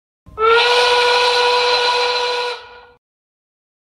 Tiếng Gầm Xịt khói tức giận hoạt hình…
Tiếng Gầm Tức Giận… giọng nữ, hoạt hình Tiếng Xì khói, Phụt khói… khi tức giận
Thể loại: Hiệu ứng âm thanh
Description: Tiếng gầm hung dữ, âm thanh nổi giận, tiếng gào thét phẫn nộ, hiệu ứng âm thanh hoạt hình.
tieng-gam-xit-khoi-tuc-gian-hoat-hinh-www_tiengdong_com.mp3